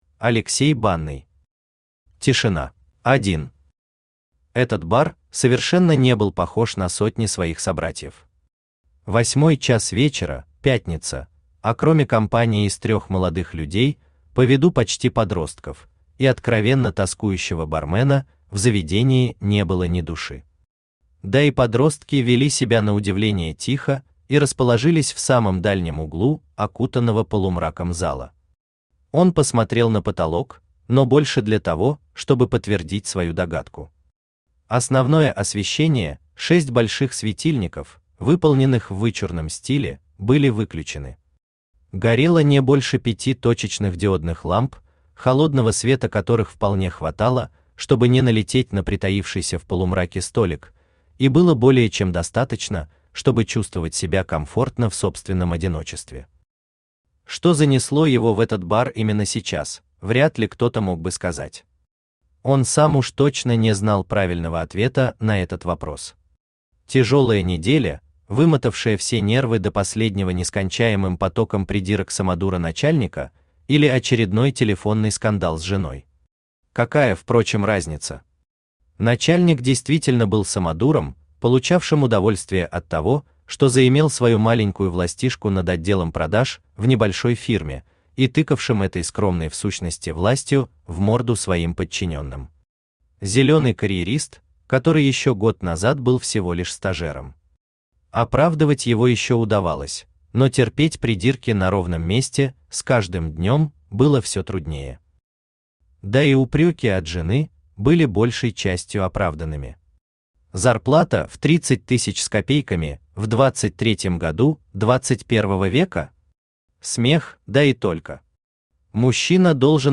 Аудиокнига Тишина | Библиотека аудиокниг
Aудиокнига Тишина Автор Алексей Сергеевич Банный Читает аудиокнигу Авточтец ЛитРес.